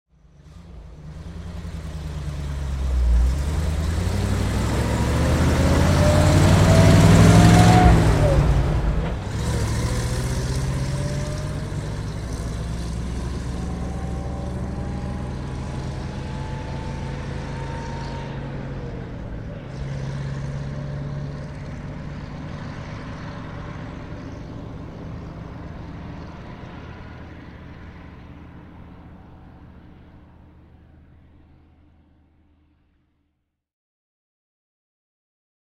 Звук уезжающего эвакуатора